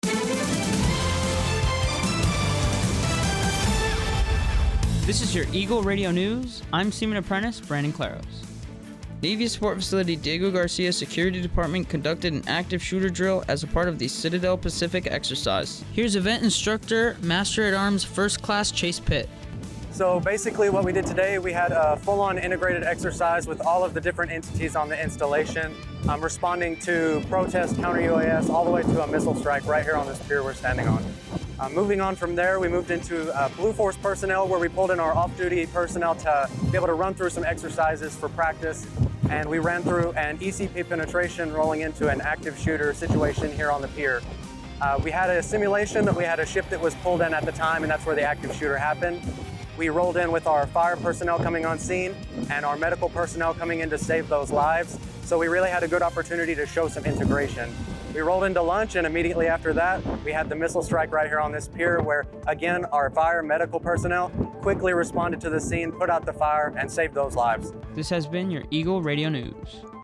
Eagle Radio News is the American Forces Network Diego Garcia’s official radio newscast.